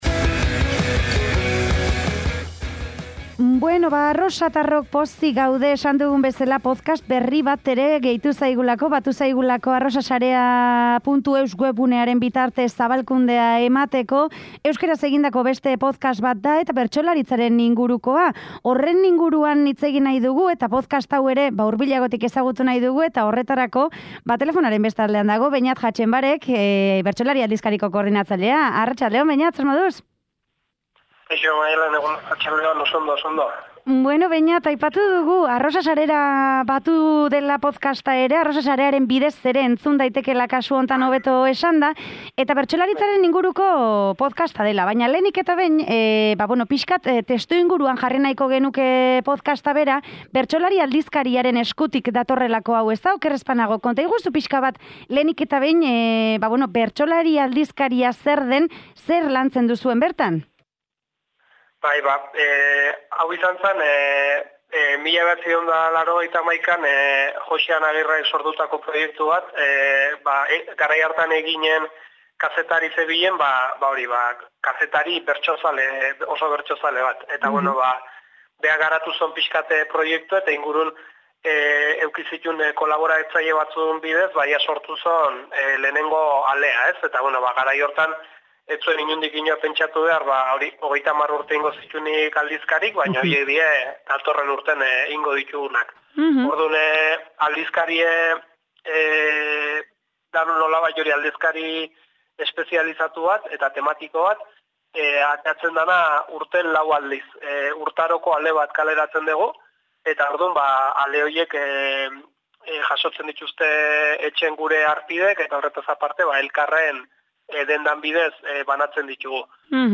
Bertsolari bati emango zaio ahotsa atal bakoitzean, bertsolaritzaz eta hamaika aferez hitz egiteko.